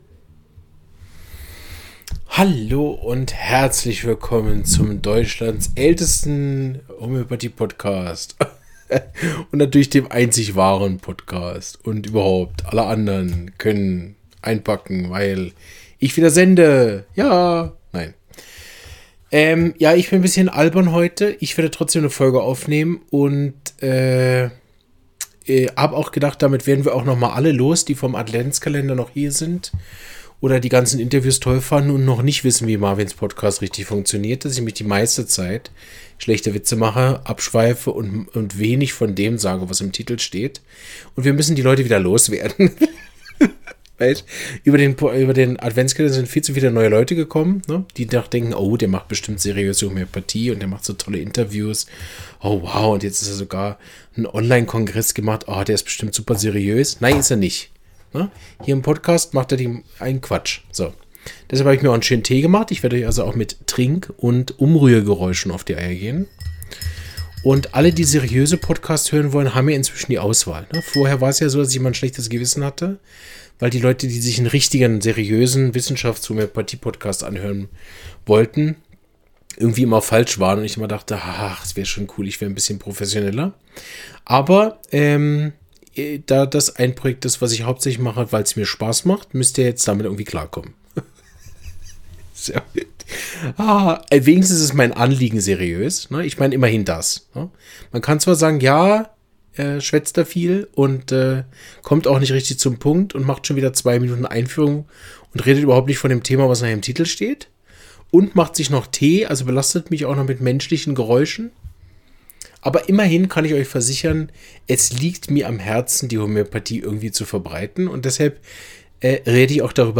Zunahme von Krebs in der homöopathischen Praxis (Krebskongress Zug) 4 mrt · Homöopathie - Podcast Later beluisteren Later beluisteren Markeren als afgespeeld Beoordeel Downloaden Ga naar de podcast Delen